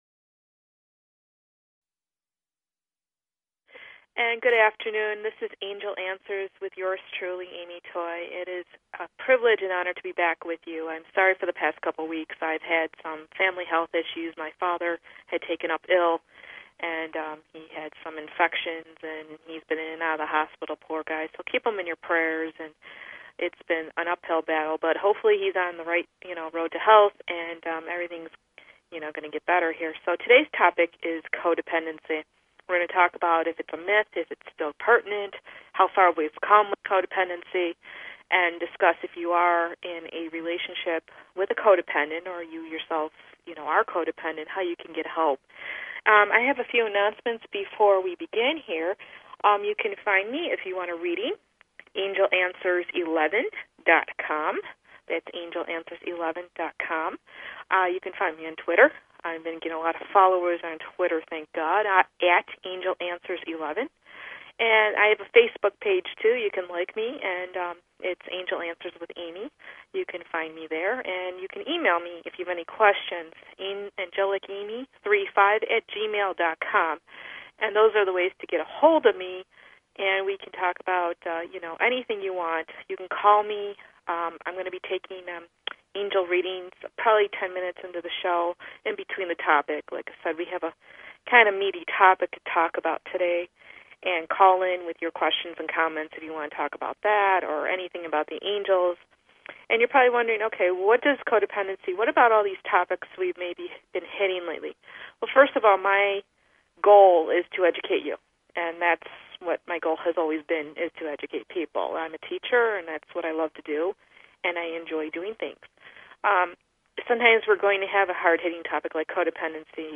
Talk Show Episode, Audio Podcast, Angel_Answers and Courtesy of BBS Radio on , show guests , about , categorized as